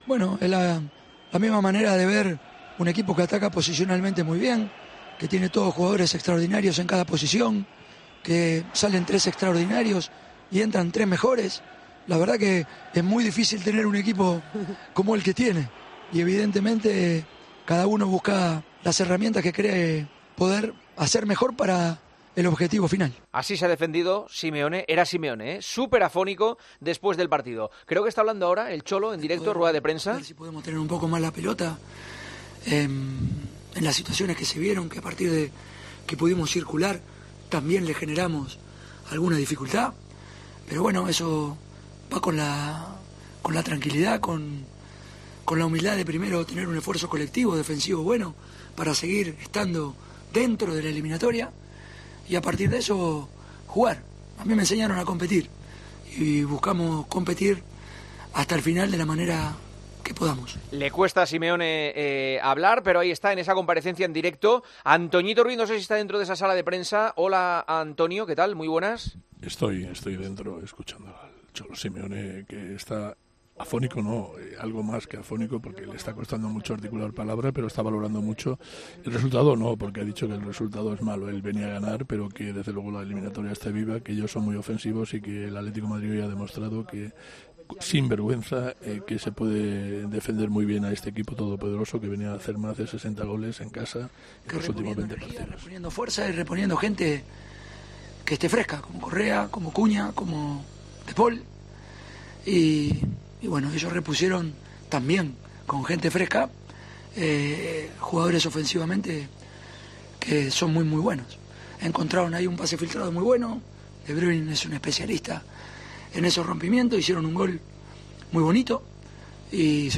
AUDIO: El entrenador del Atlético de Madrid analizó la derrota por la mínima ante el Manchester City en la ida de los cuartos de final de la Champions.